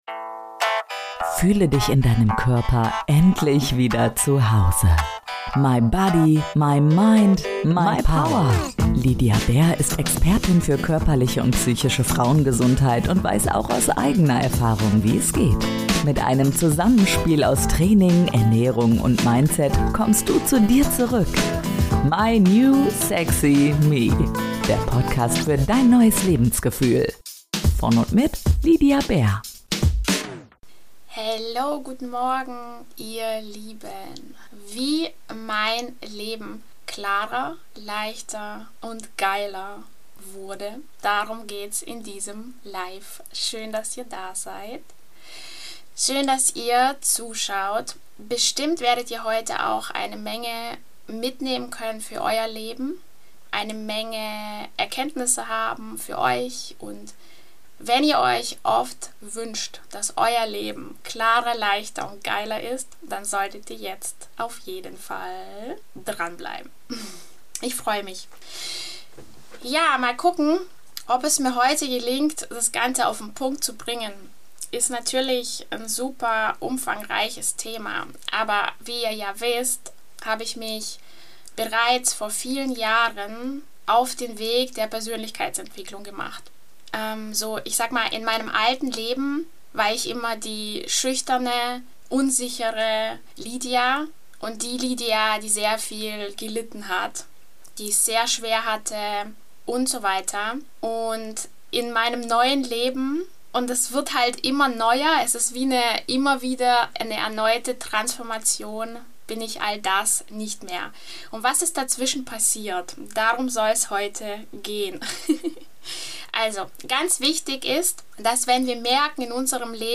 In diesem Instagram Live spreche ich ganz offen über meine persönliche Weiterentwicklung und Veränderung. Ich erzähle Dir was ich in den letzten Wochen transformieren durfte, wie ich meine Identität verändert habe und welche Folgen das hatte.